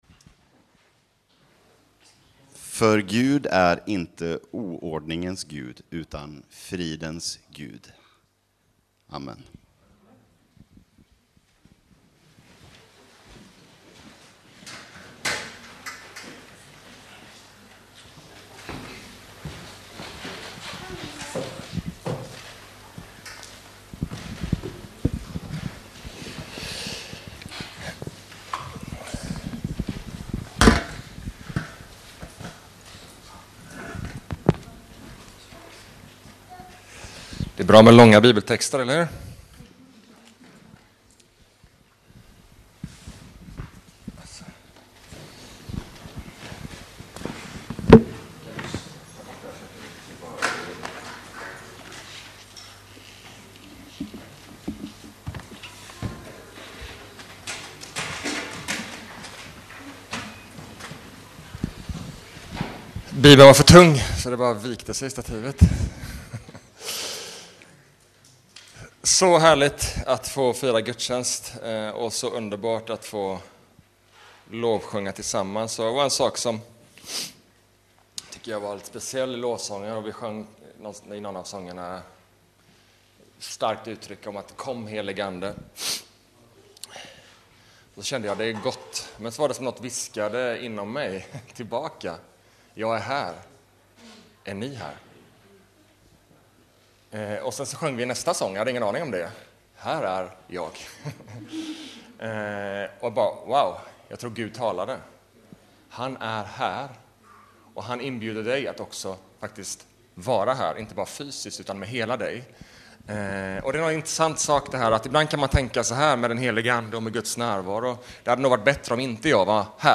Launch Sermon Player Leva livet med den helige ande